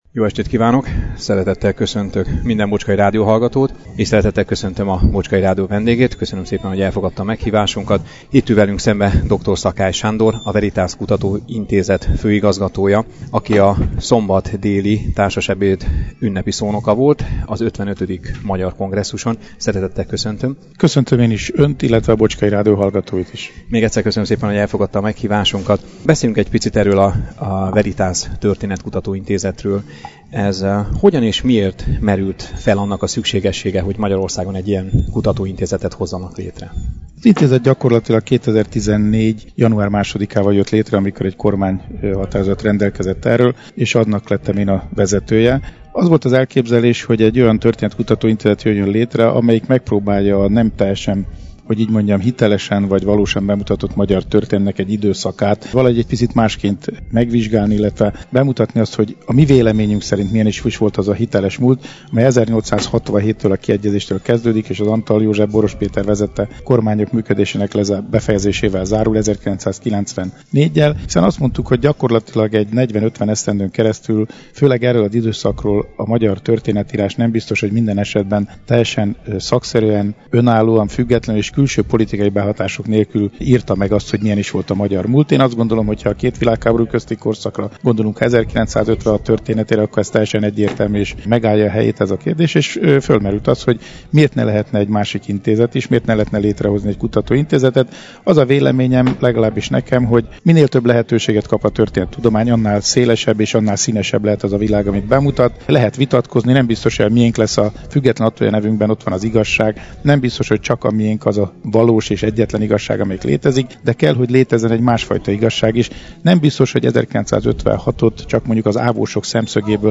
Interjú Dr. Szakály Sándorral – Bocskai Rádió
Természetesen szívesen ült le a Bocskai Rádió mikrofonja mögé, ahol először az általa vezetett intézetről beszélgettünk, annak működéséről, hasznosságáról. Majd elmondta véleményét a mostani magyarországi történelem tanításról, amelybe lát hiányosságokat, kiegészítésre szoruló területeket, azért, hogy a jövő nemzedéke lehetőséghez mérten Magyarország igaz történelmét tanulhassa. A külföldön élő magyarokról is beszélgettünk, a megmaradásuk esélyeit latolgatta a professzor úr, példaként említve az éppen zajló Magyar Kongresszust, amely úgyszintén egy ilyen szerepet tölt be.